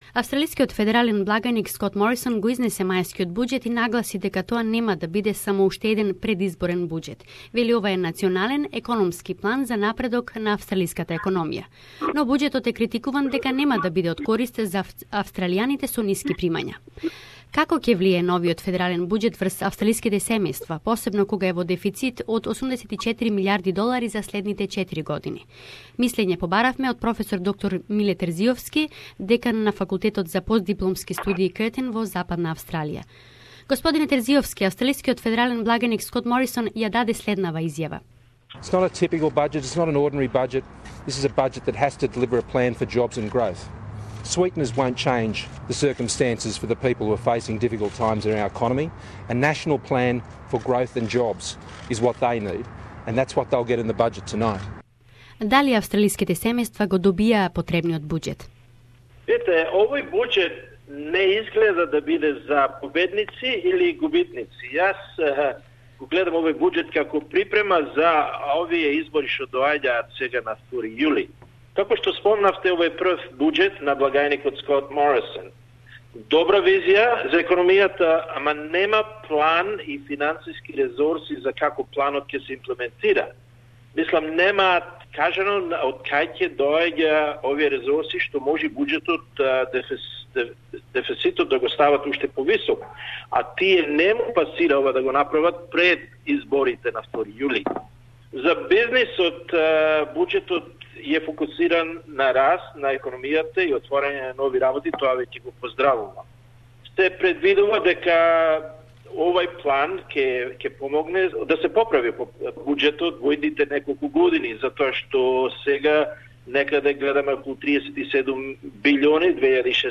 The federal budget has a great vision for the future, but no action plan and resources to implement such plans. innovation and entrepreneurship are the future of this country. The full interview